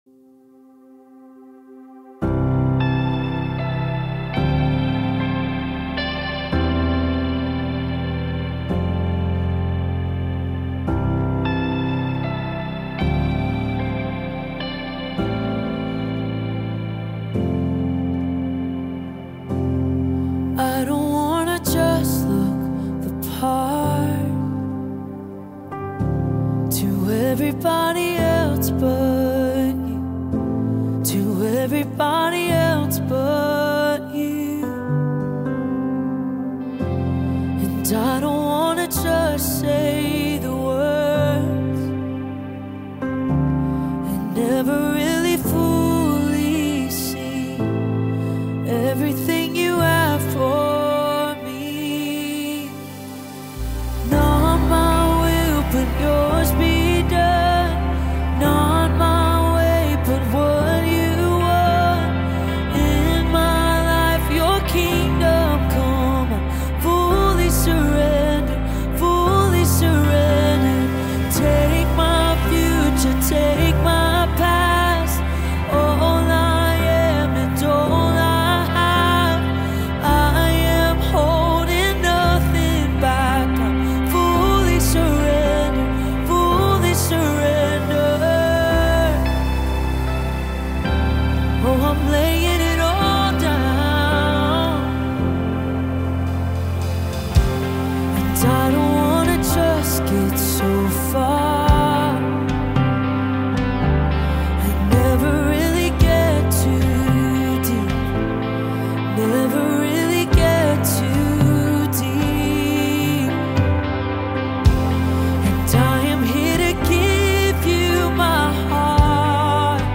9 просмотров 10 прослушиваний 0 скачиваний BPM: 111